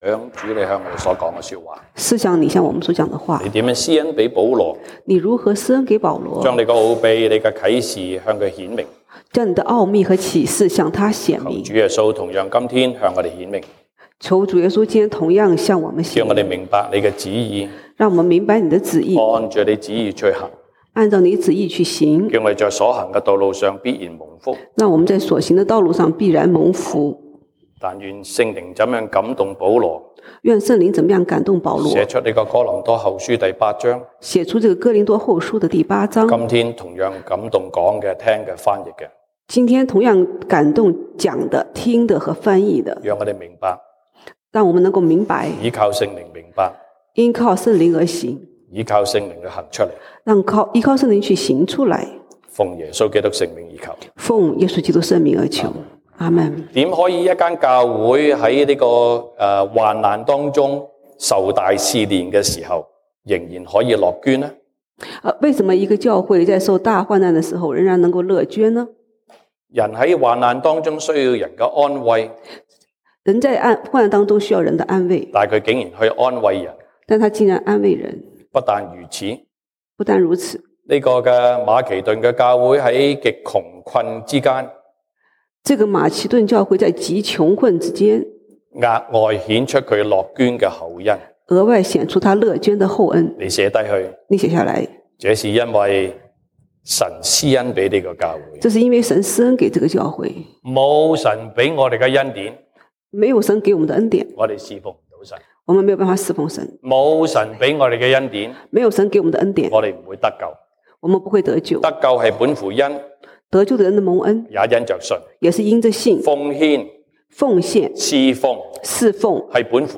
西堂證道(粵語/國語) Sunday Service Chinese: 樂捐的厚恩
Passage: 歌林多後書 2 Corinthians 8:5-24 Service Type: 西堂證道(粵語/國語) Sunday Service Chinese